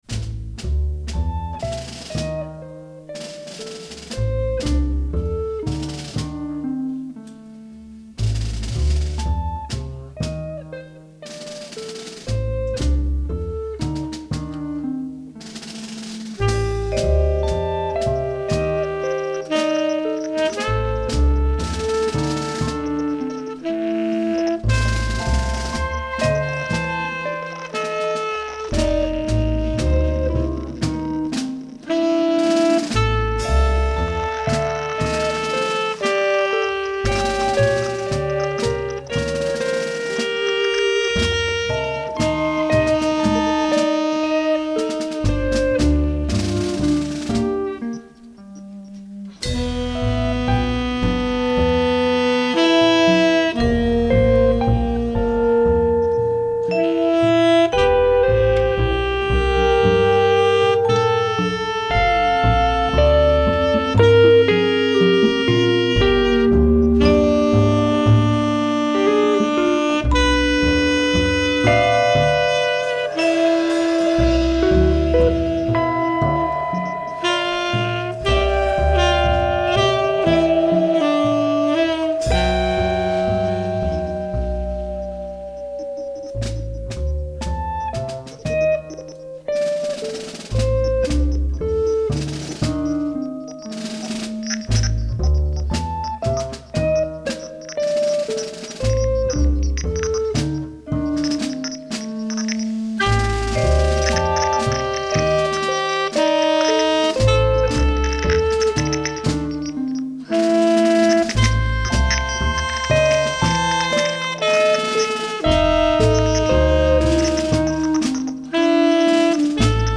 tenor saxophone
electric guitar
acoustic bass
drums
percussion
electronics